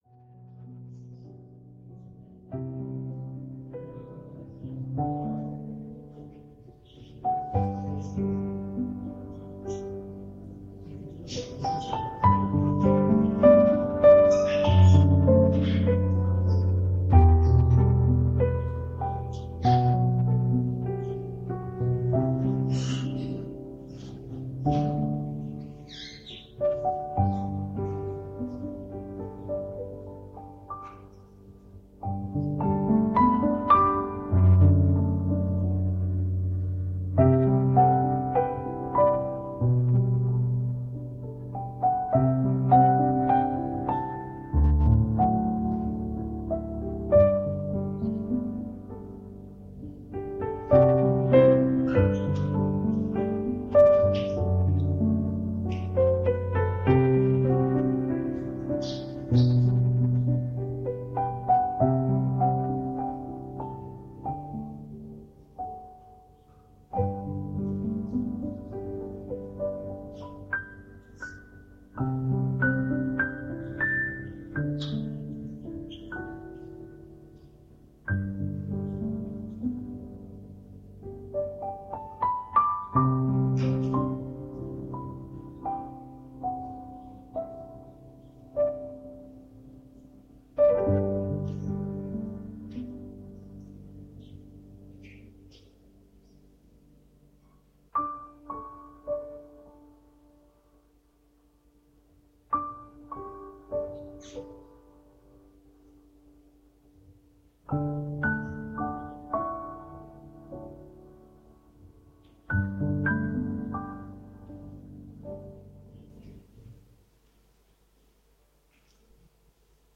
Alliance teenistuse palvenädala teisipäevane osadus toimus Haapsalu Adventkirikus.
Koosolekute helisalvestused